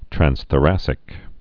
(trănsthə-răsĭk)